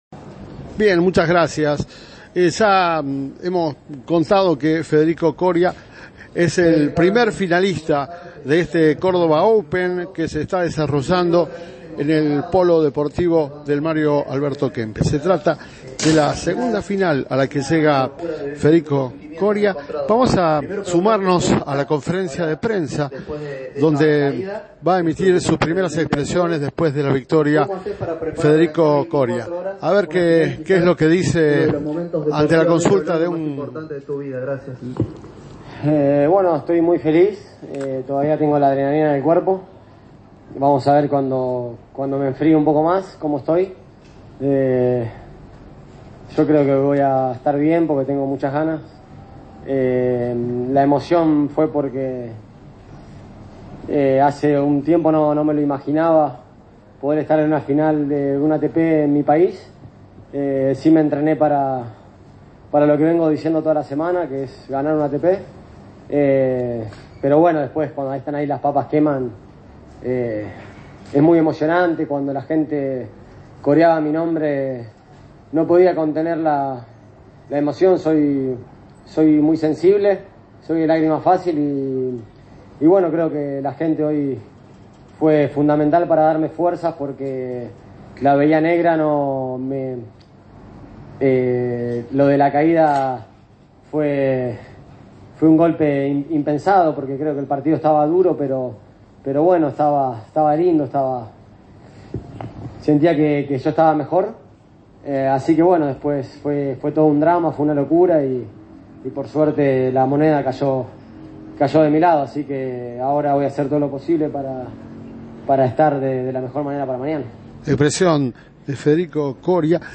"Estoy muy feliz porque hace un tiempo no me imaginaba poder estar en la final de un ATP en mi país, pero entrené para esto", dijo Coria en conferencia de prensa una vez finalizado el partido.